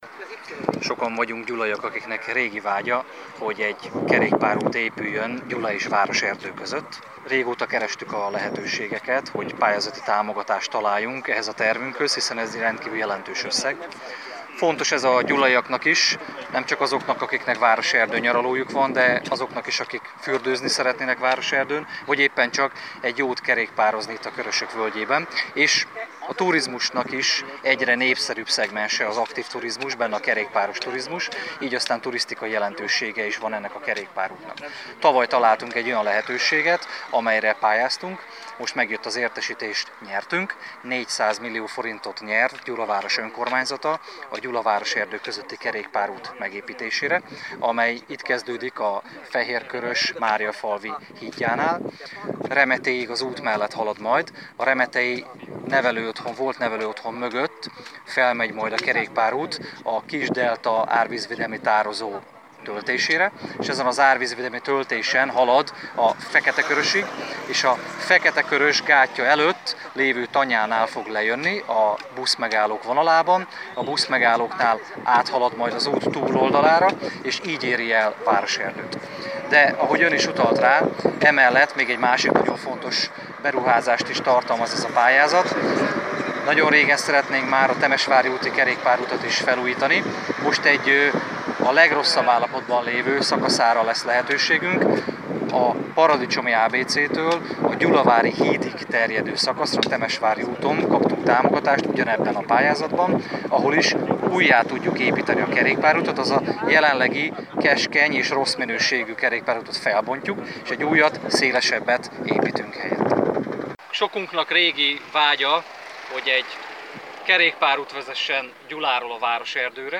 Kerékpáros bejárással egybekötött sajtótájékoztatót tartott Dr. Görgényi Ernő polgármester és Alt Norbert a körzet képviselője, alpolgármester.